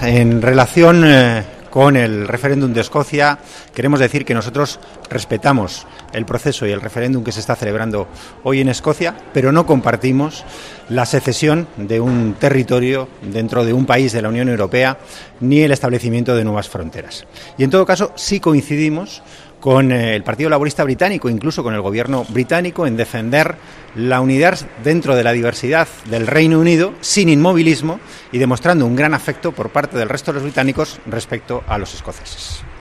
Declaraciones de Antonio Hernando sobre la consulta en Escocia 18-09-14